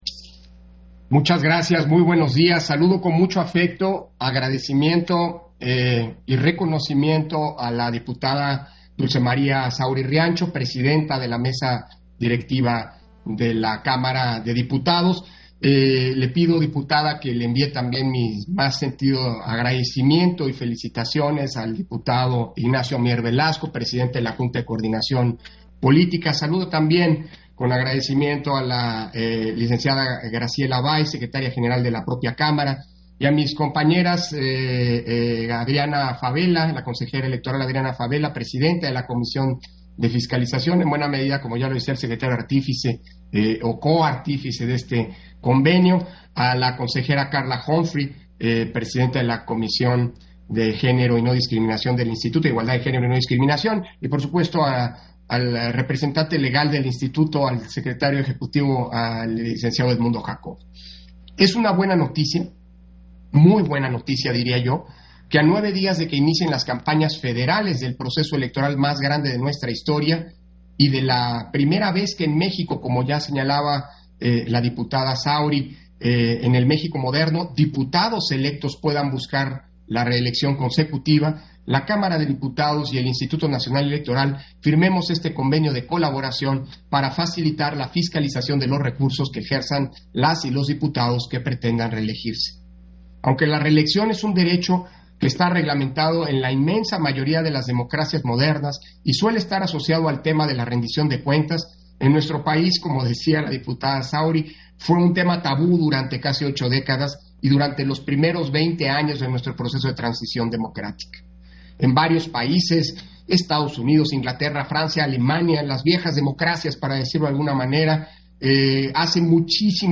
Intervención de Lorenzo Córdova, durante la firma del convenio con la Cámara de Diputados para mecanismos de fiscalización, verificación y comprobación de recursos de legisladoras/es que busquen elección consecutiva